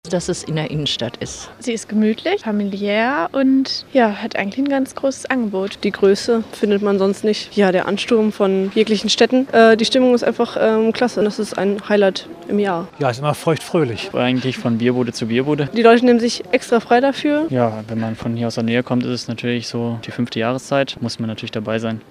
Umfrage Allerheiligenkirmes